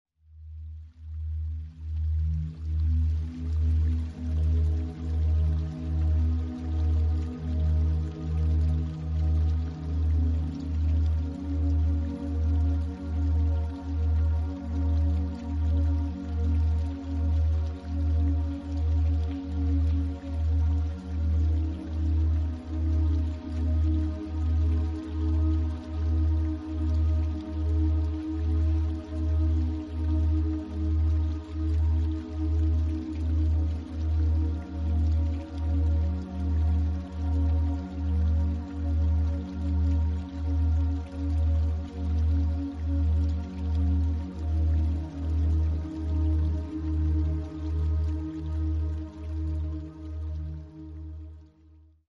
expierence the calming effect of delta binaural beats